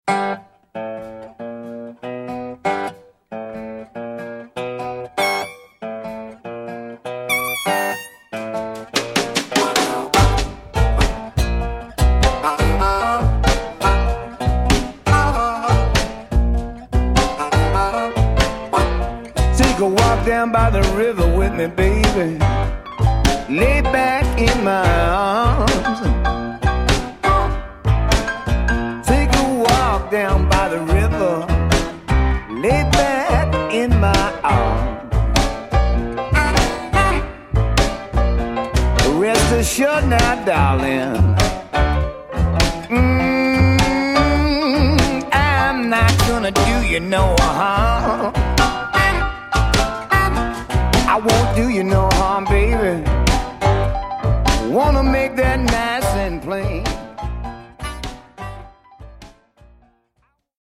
vocal & harmonica
I intentionally led one lyric into the next.